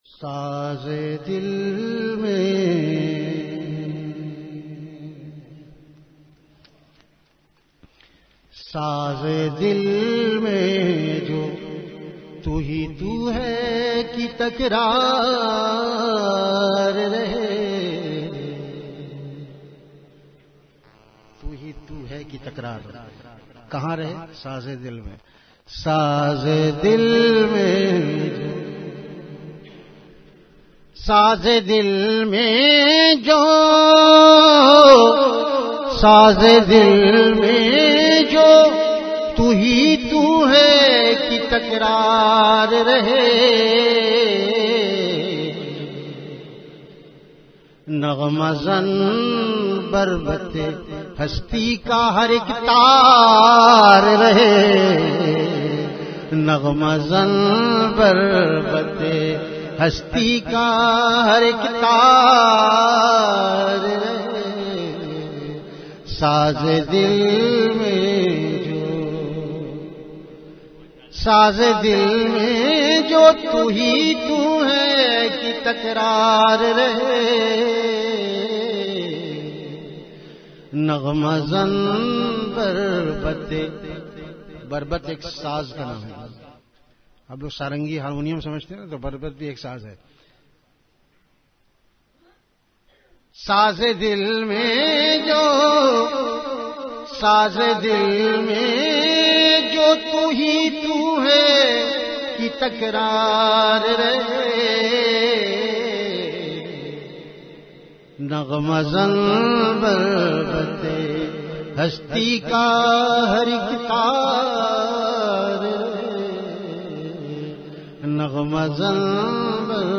Category Ashaar
Event / Time After Isha Prayer